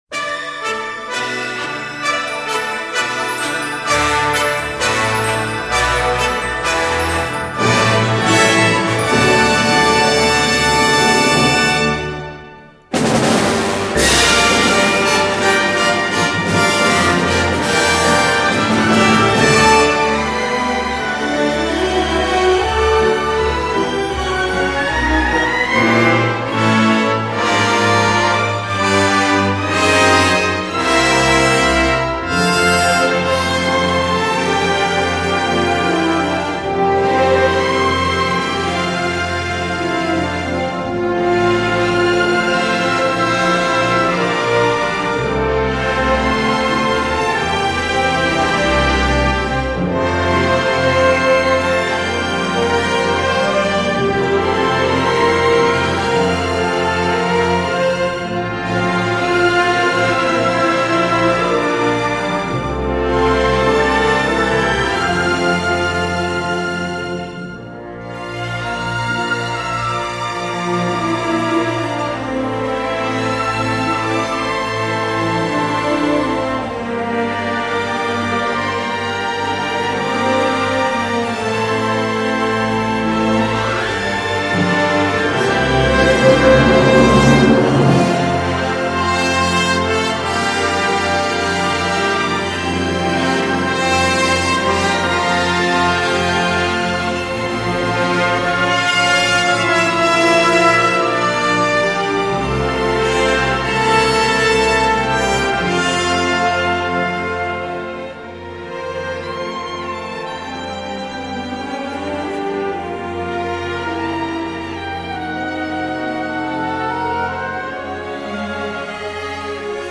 présentés sous la forme de mini-suites orchestrales.